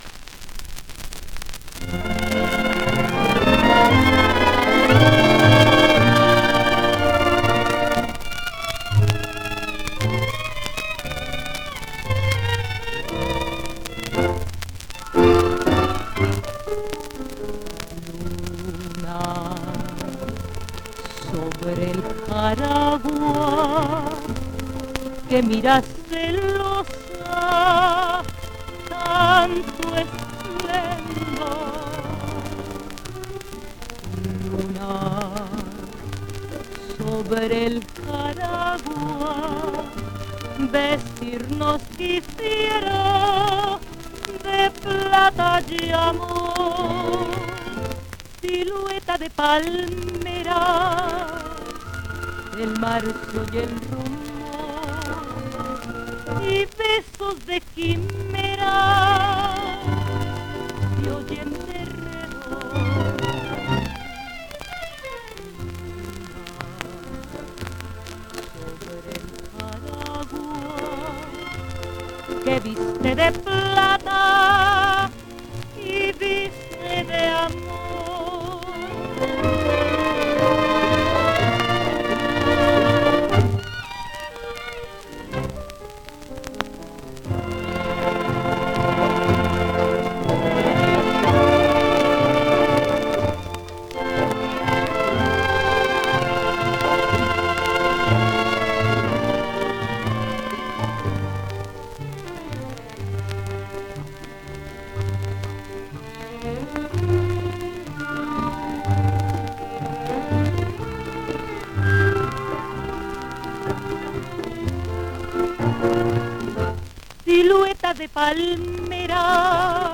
1 disco : 78 rpm